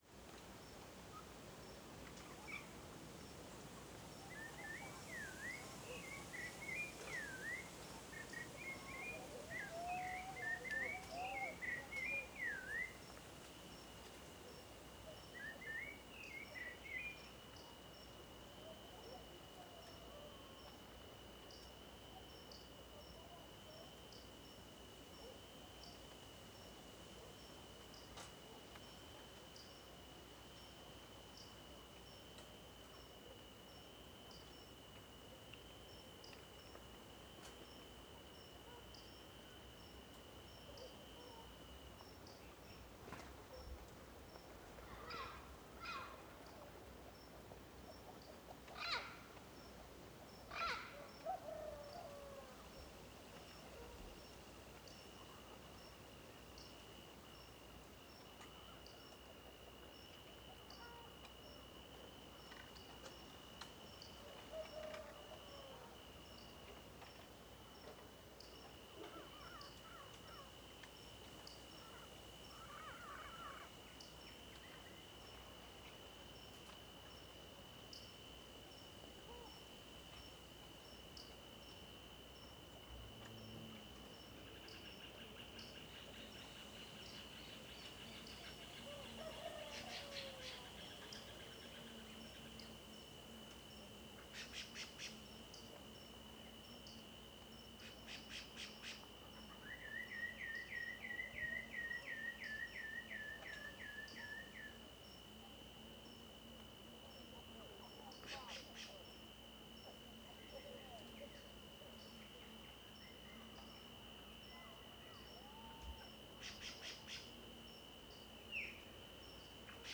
CSC-04-158-GV - Ambiencia na Estrada de Terra para Pousada Inacia com Passaros e Grilos em Matagal Proximo.wav